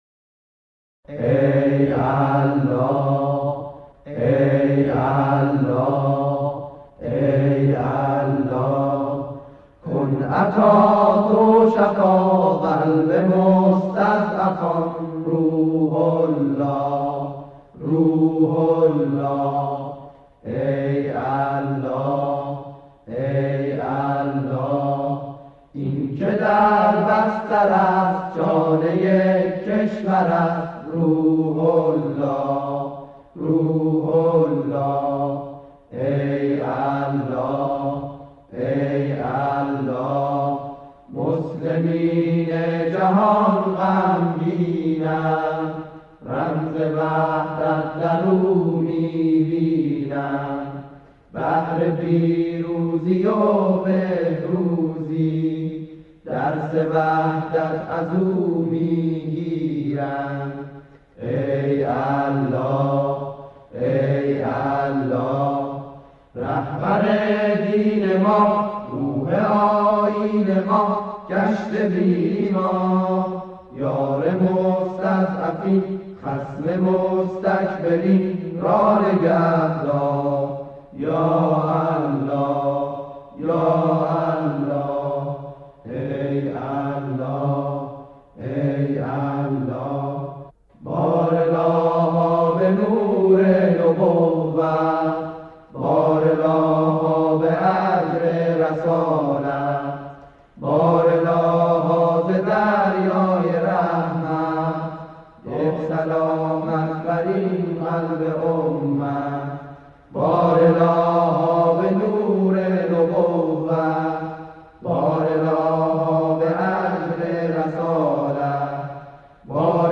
گروهی از جمعخوانان